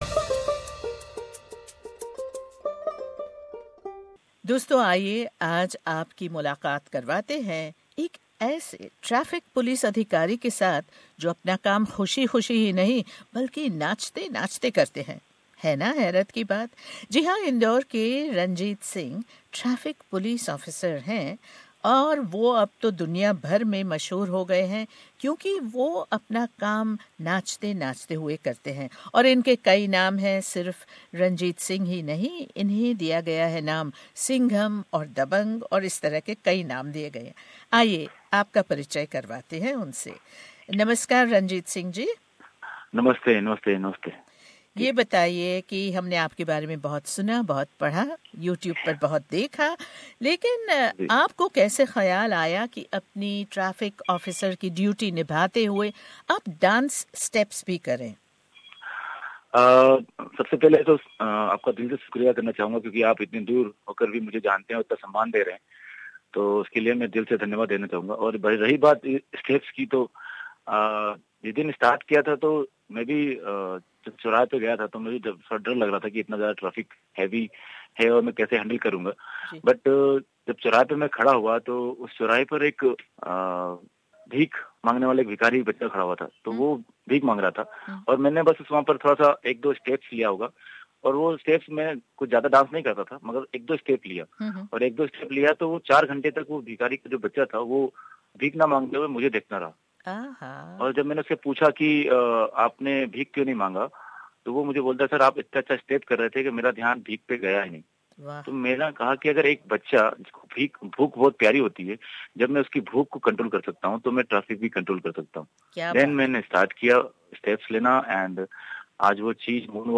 He has been interviewed by media channels all round India, Bangla Desh, Pakistan, UK, UAE, China and more.